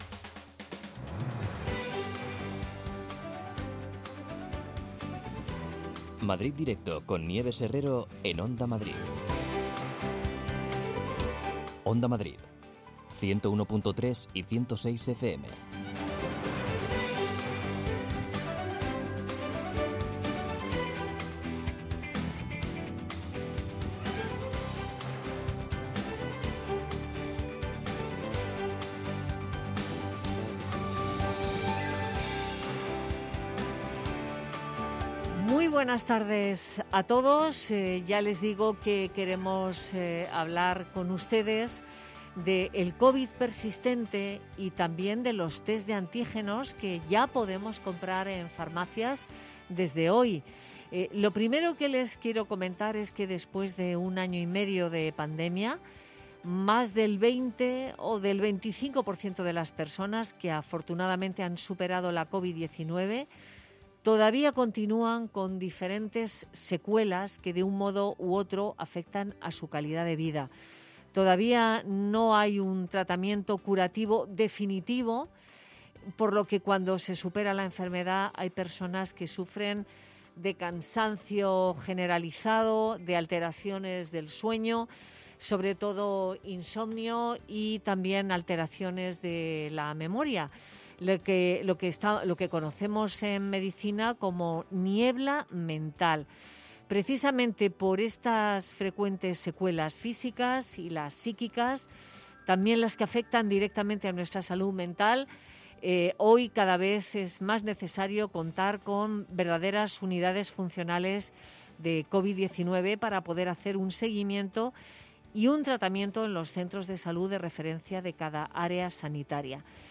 Nieves Herrero se pone al frente de un equipo de periodistas y colaboradores para tomarle el pulso a las tardes. Cuatro horas de radio donde todo tiene cabida.